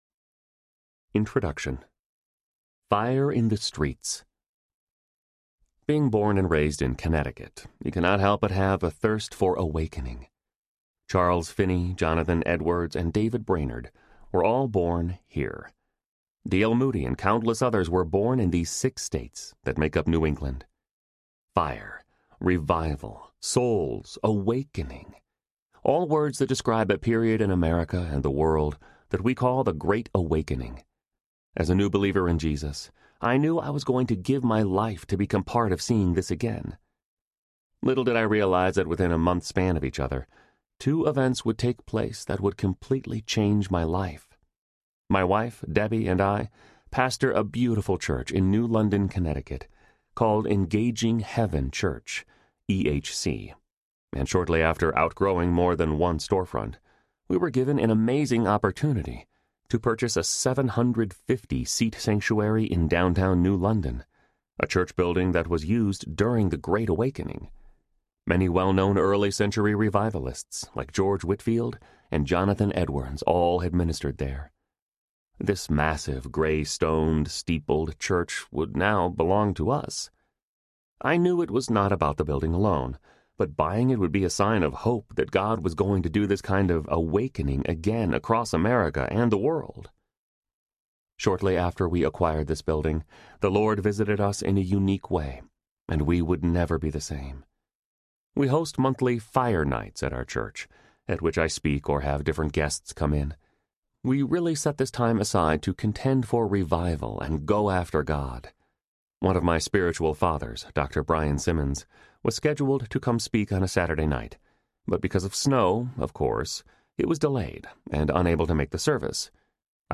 Fire! Audiobook
Narrator
5.3 Hrs. – Unabridged